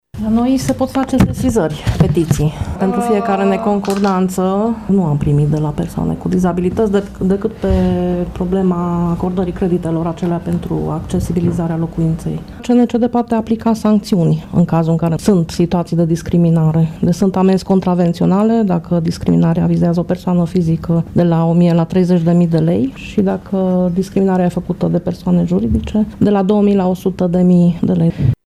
Aceasta este concluzia mesei rotunde de astăzi de la sediul Fundației Alpha Transilvană din Tîrgu-Mureș, la care au participat reprezentanți ai ONG-urilor care au ca obiect drepturile persoanelor cu dizabilități și reprezentanți ai administrației locale.